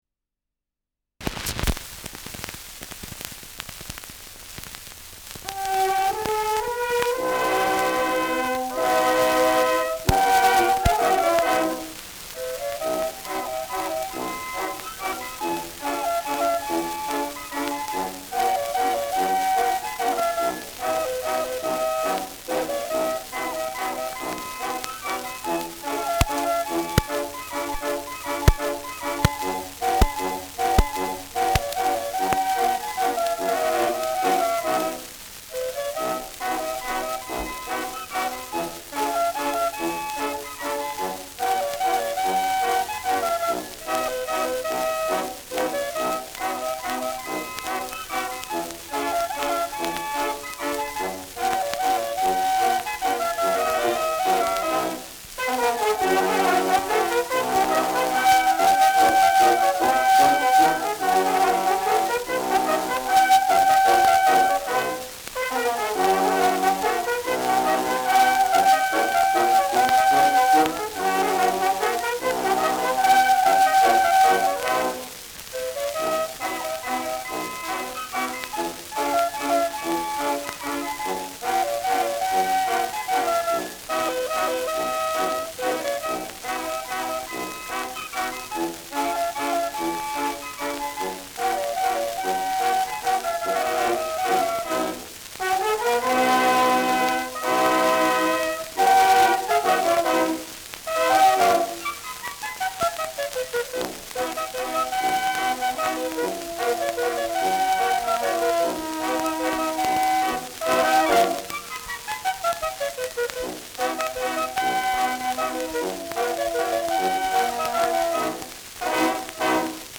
Schellackplatte
Starkes Grundrauschen : Starkes Knacken zu Beginn : Hänger im ersten Drittel : Auffällig langsam
Wiener Bürgerkapelle (Interpretation)
[Wien] (Aufnahmeort)